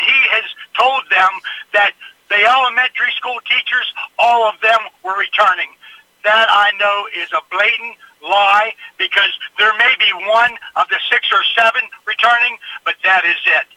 Caller Updates On Calvary Christian Academy
A caller to WCBC on Thursday morning brought new information about the situation at Calvary Christian Academy in Cresaptown.